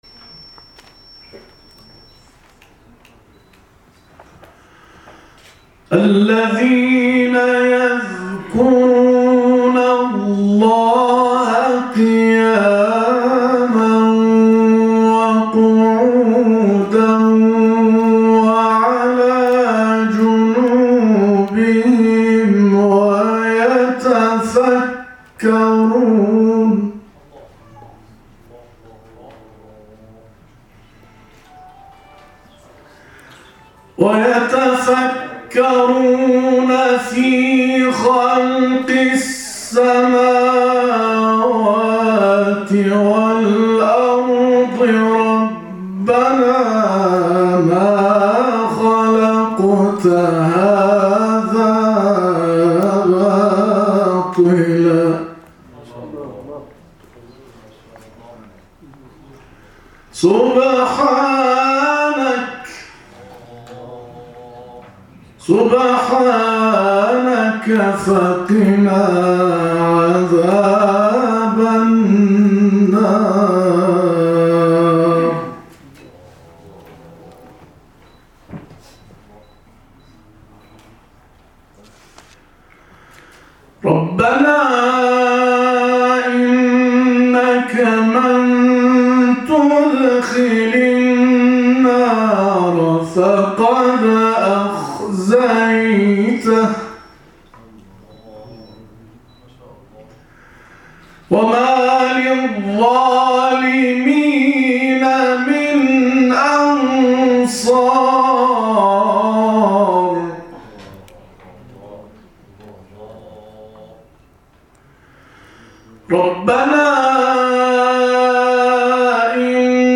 تلاوت کوتاه
در سالن آمفی تئاتر سازمان اوقاف و امور خیریه